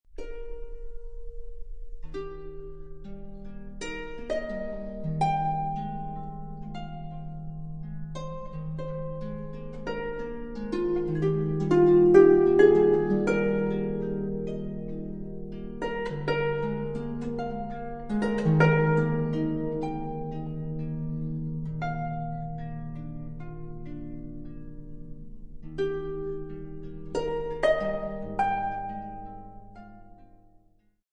an album of superb Russian solo harp music
modern concert harp